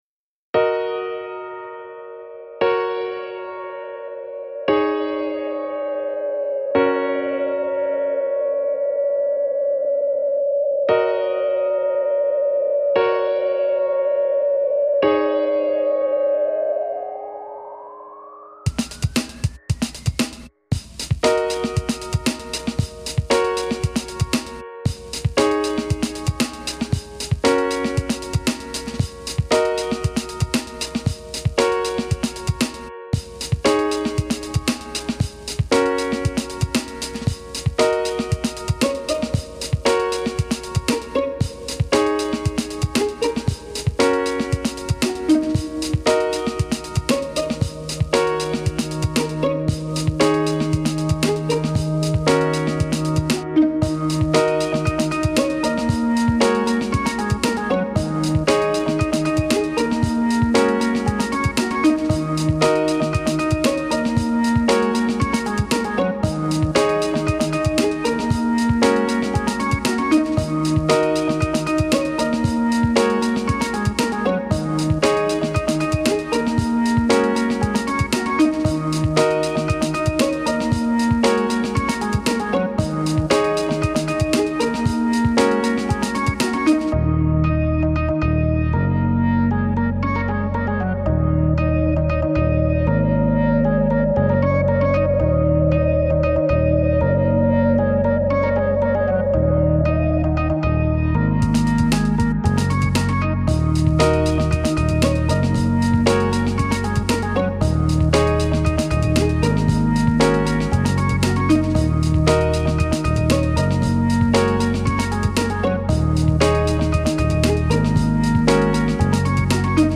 A downbeat hip hop kinda tune with plenty of organ